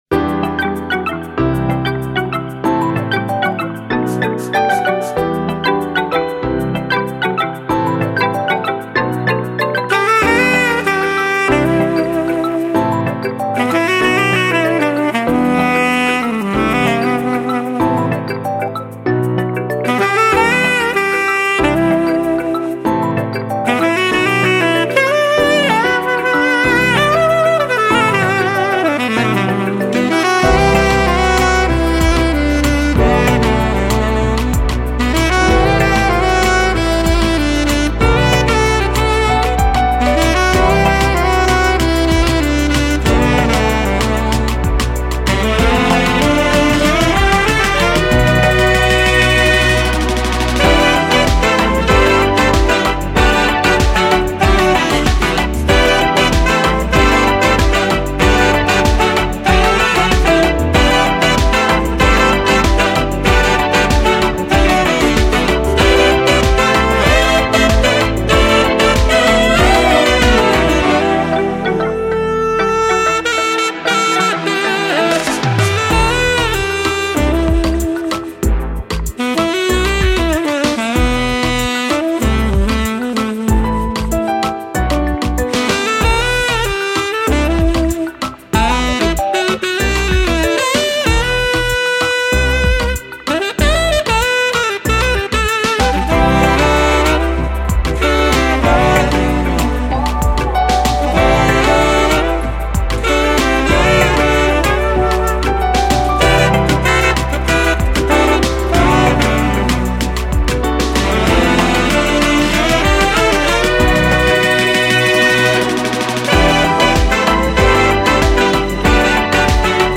R&B • Surabaya